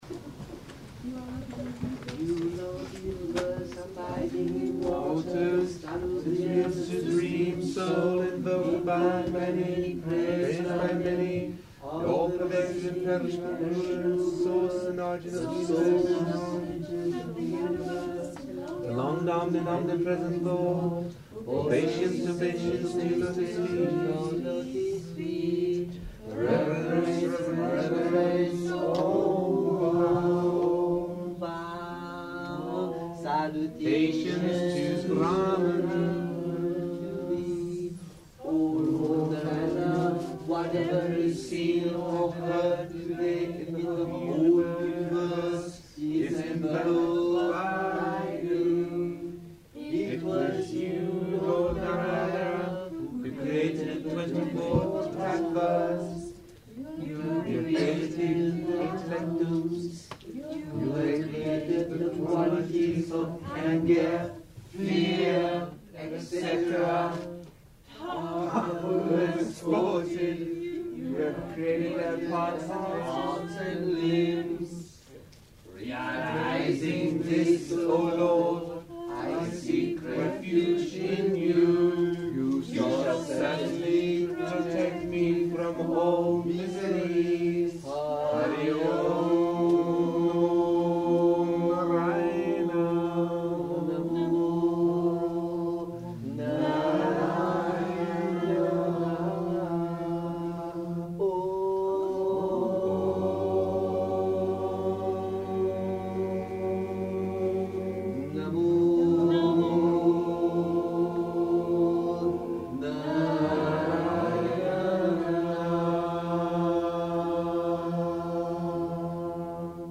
Amen There’s a long arati too Listen to it being sung at a kirtan in Eithin on September 24 1983 .